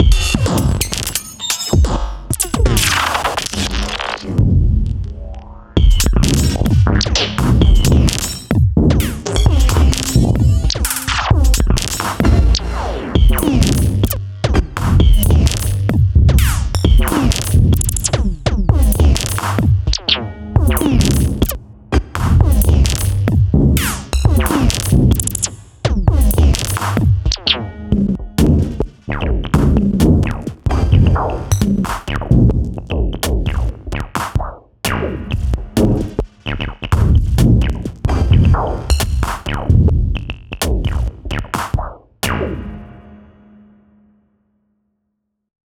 Techno System + Swarm :slightly_smiling_face: